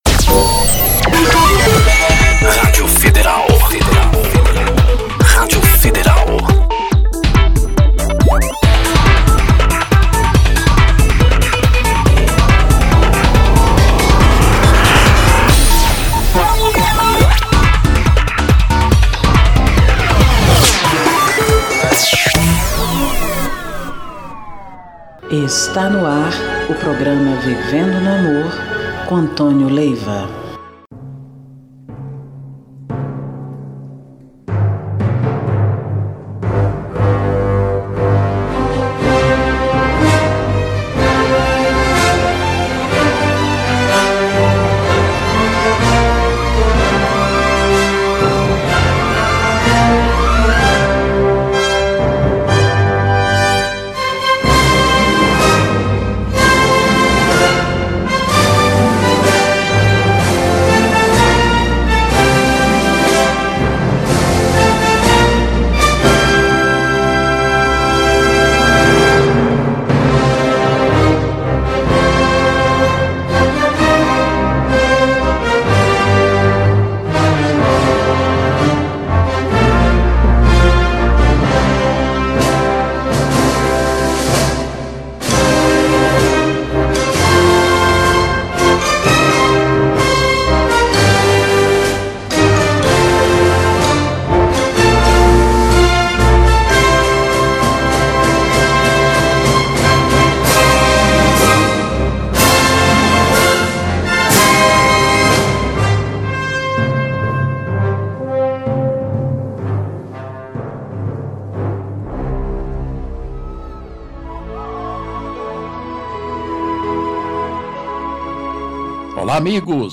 MÚSICAS E MENSAGENS